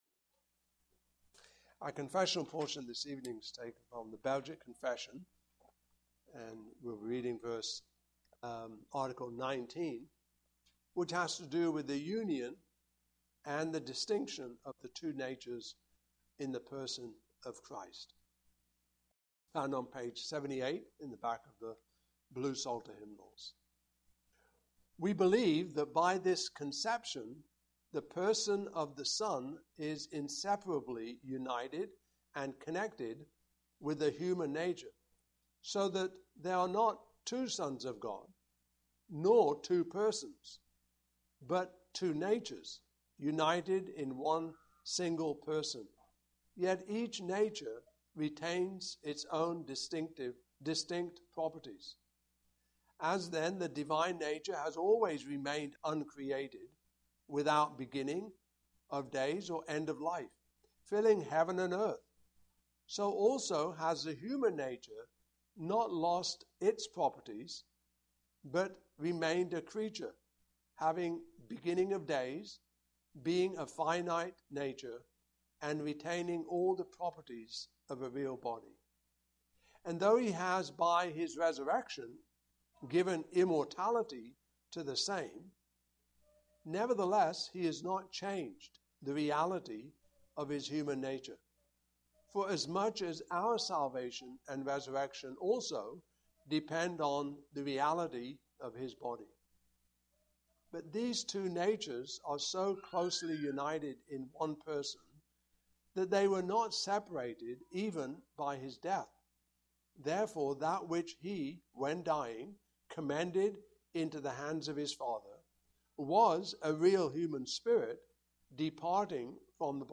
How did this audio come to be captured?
Passage: Hebrews 1:1-14; 2:1-10 Service Type: Evening Service